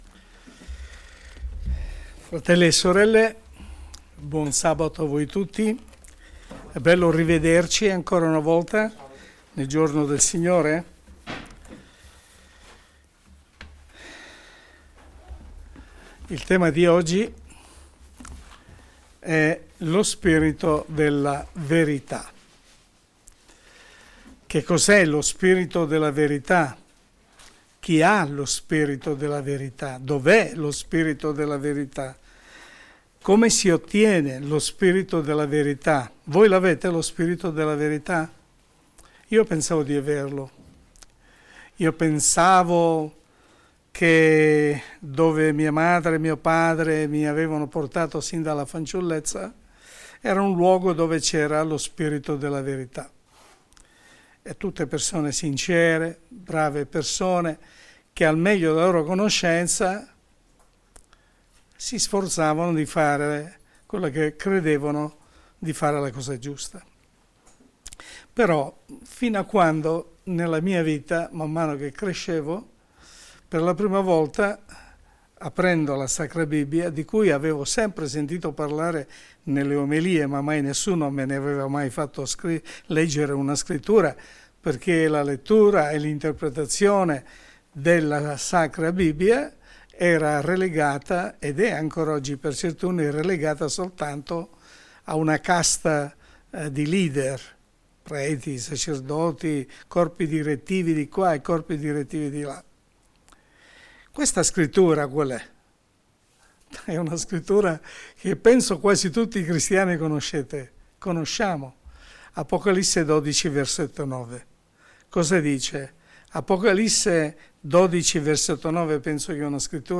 in questa babilonia globale delle religioni come ottenere la verità? ascolta per scoprirne il segreto (Sermone pastorale